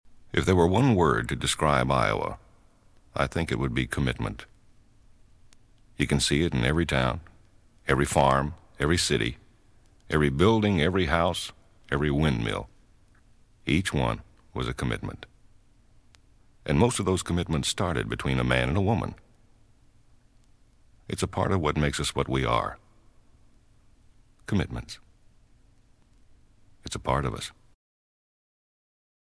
This folk music
drums
piano
bass guitar
harmonica
banjo
strings
vocals
Folk music--Iowa